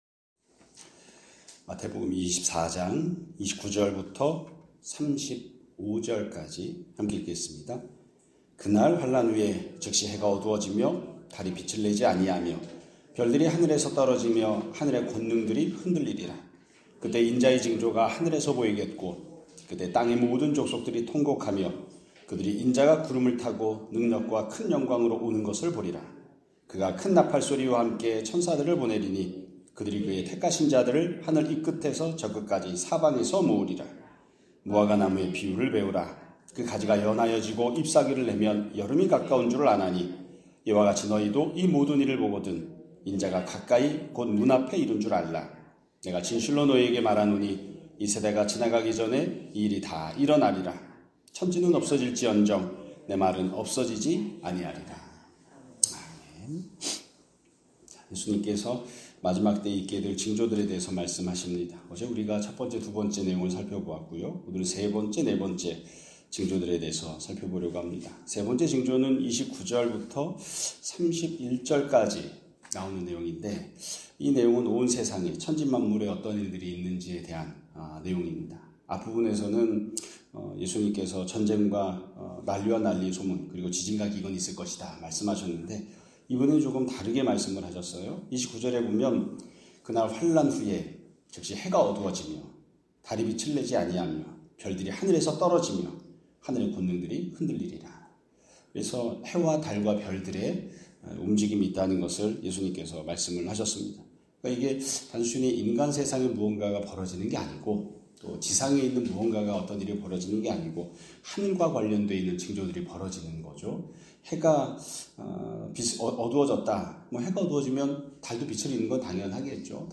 2026년 3월 12일 (목요일) <아침예배> 설교입니다.